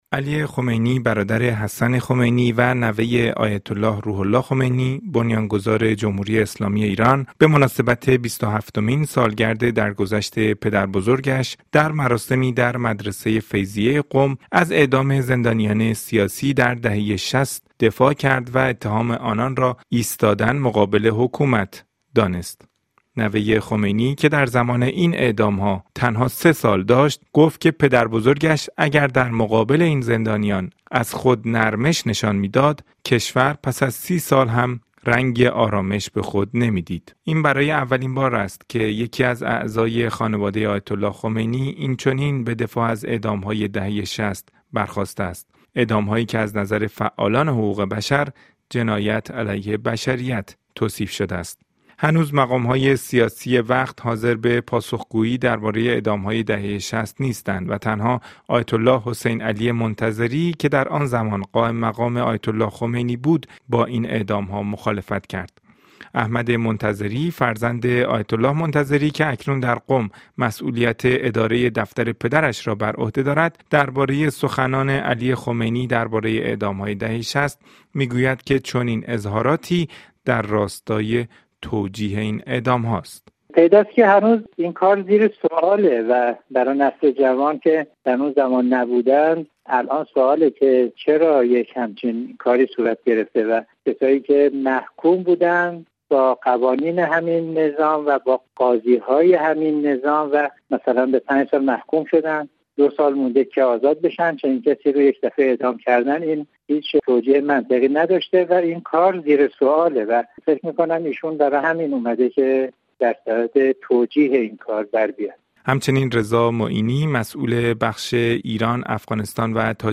خمینی‌ها، اصلاح‌طلبان و اعدام‌های دهه شصت؛ گفت‌وگو